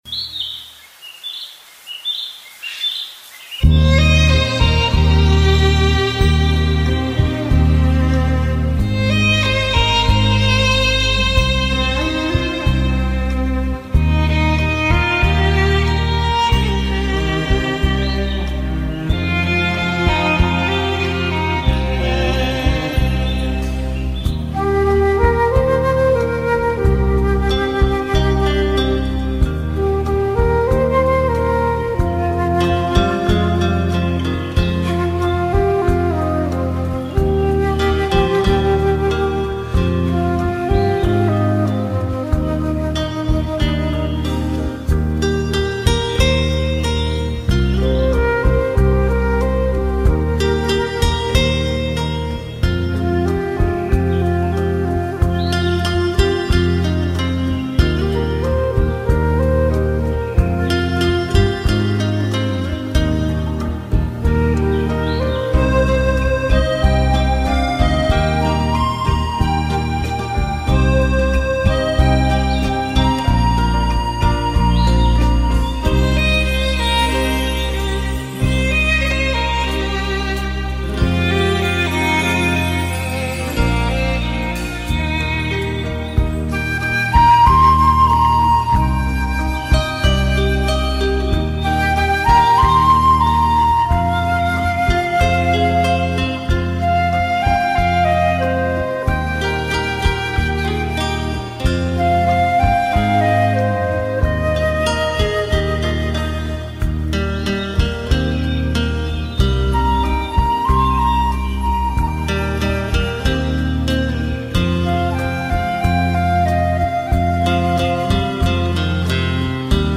小 中 大 求助：一首外国曲子的名字和出处 有试听~ 刚看到《鸟之诗》的帖子，忽然想到这个曲子（可惜曲子不全） 很想知道这首曲子的名字和出自那个专辑 应该属于新世纪音乐风格类的，也不知是不是《鸟之诗》系列的曲子~~ 哪位知道，指点一下，多谢啦·！！！